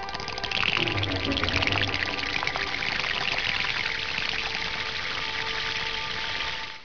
kodamas_click.wav